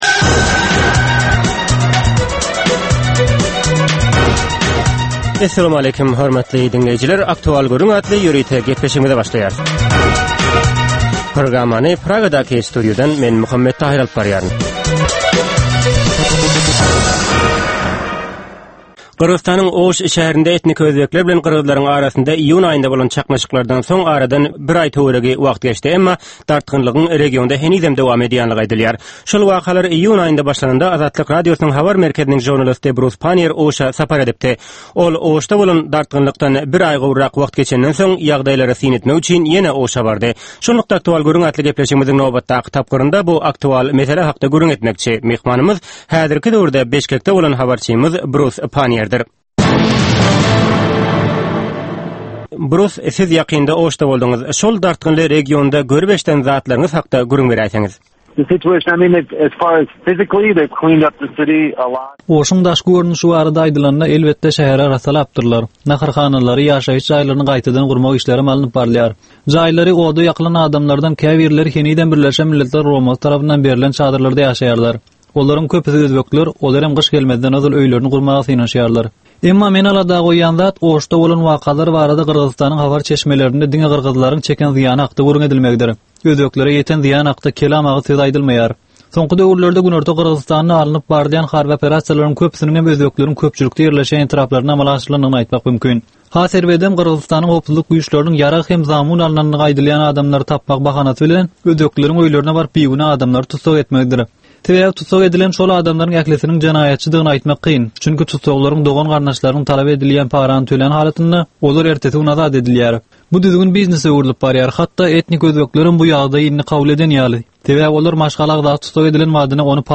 Bu söhbetdeşlikde anyk bir waka ýa-da mesele barada synçy ýa-da bilermen bilen gürrüňdeşlik geçirilýär we meseläniň dürli ugurlary barada pikir alyşylýar.